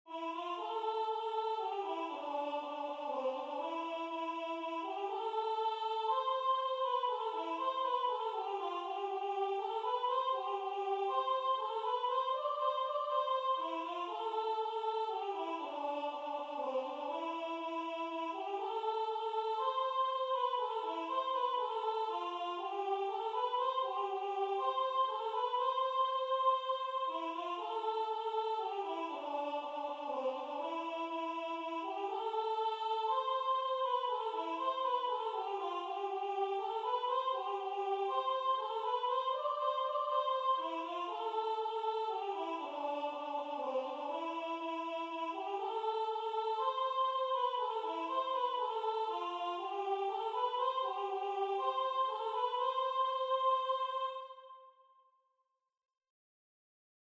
3 equal voices Genre: Sacred, Canon
Language: English Instruments: A cappella
First published: 2025 Description: This is a three voice round.